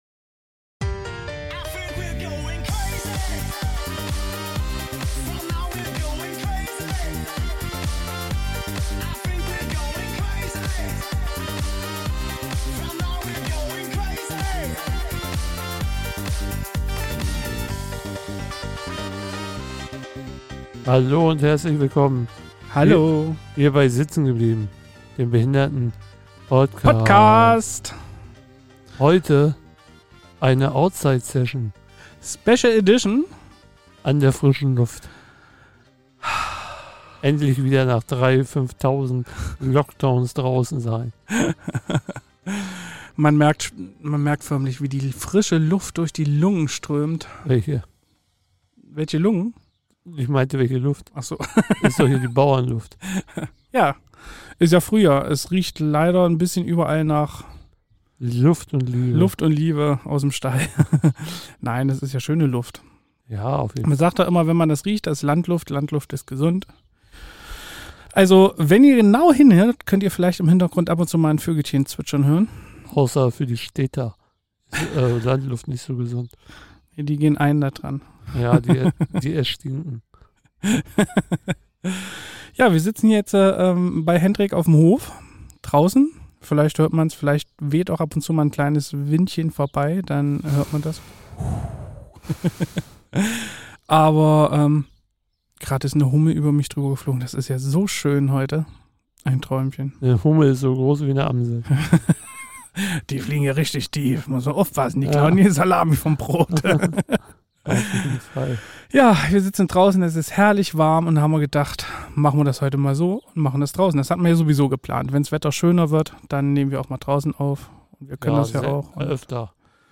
Beschreibung vor 4 Jahren Heute haben wir unser Equipment geschnappt und sind damit raus in die Sonne gezogen, um die Themen unserer kleinen Umfrage zu besprechen.
Wer übrigens die Vögel im Hintergrund an ihrer Stimme erkennt, dem spendieren wir einen Gruß in der nächsten Folge!